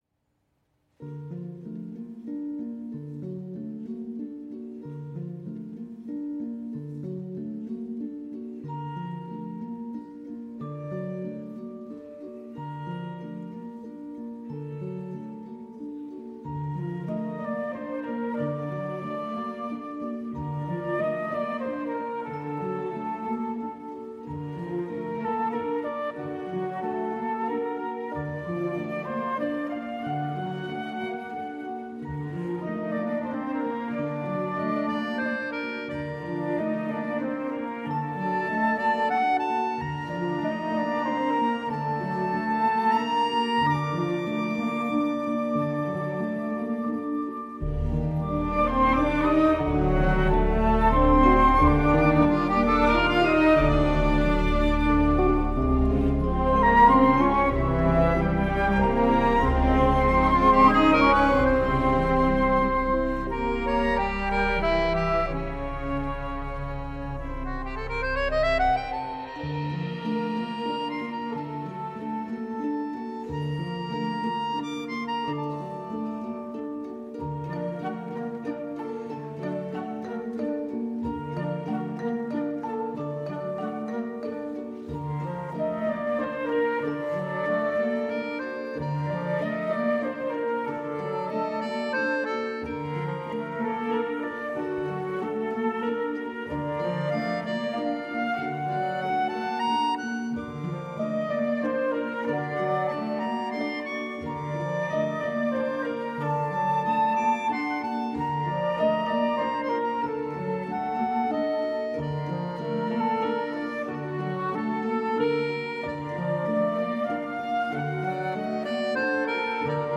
suite concertante pour flûte et orchestre